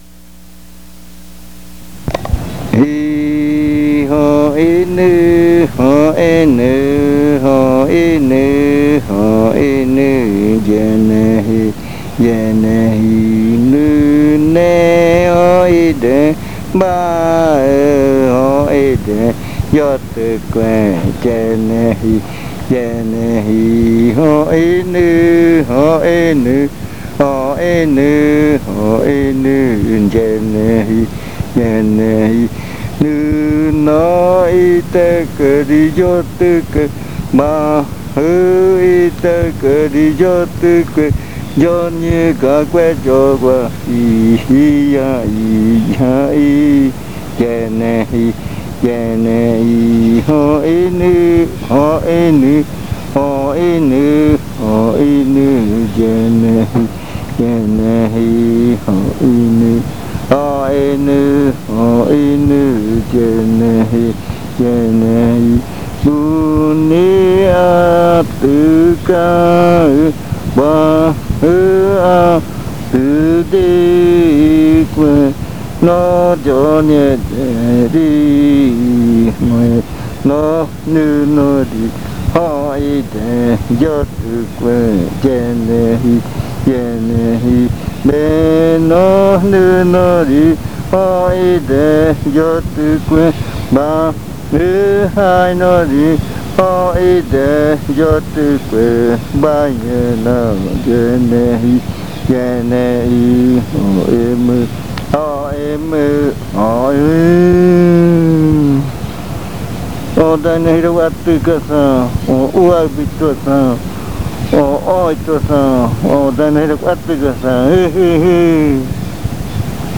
Se canta en la madrugada. Sencillamente, una canción que se canta para que los bailarines bailen.
Simply, a chant that is sung for the dancers to dance to. The chant is related to lulling in the hammock.